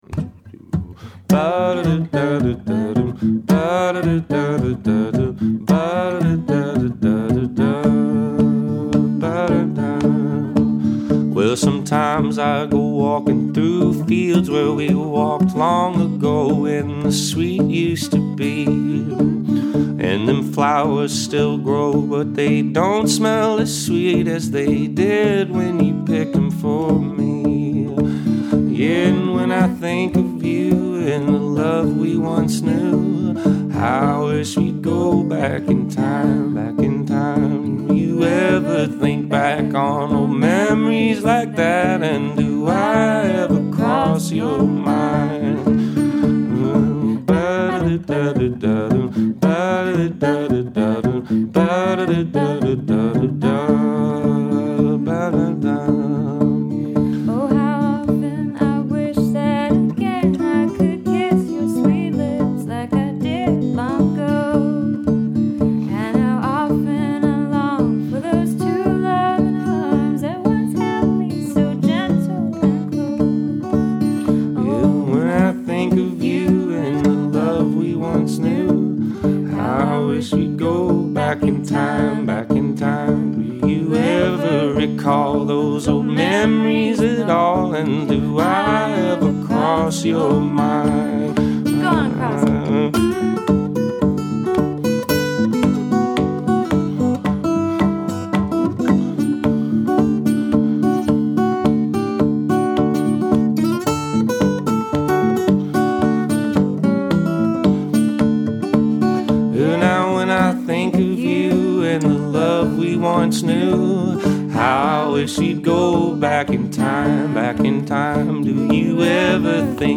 This little acoustic ditty
the youthful baritone